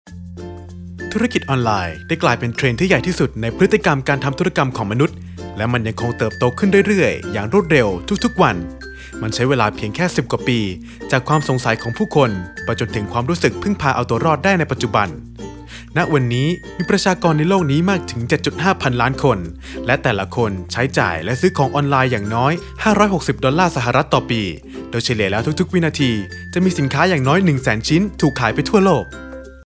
标签： 大气
配音风格： 大气 稳重 讲述 亲切 知性